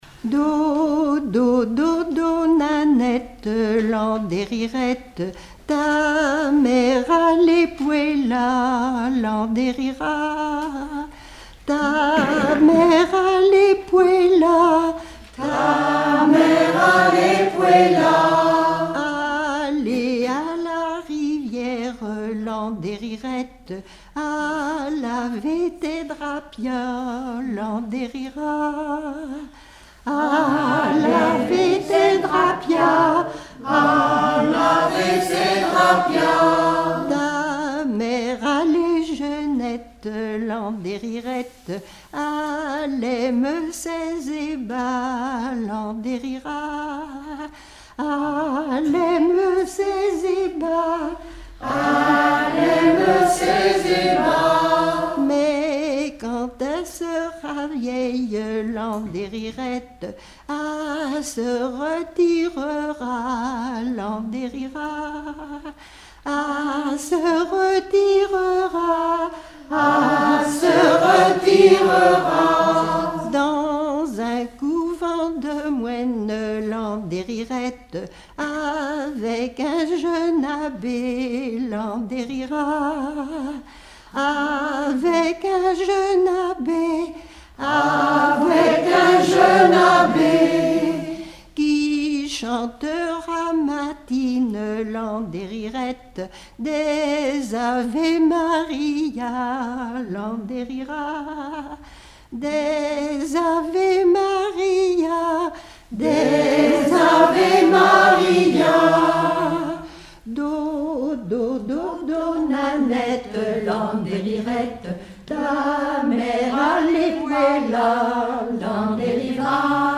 berceuse
Regroupement de chanteurs du canton
Pièce musicale inédite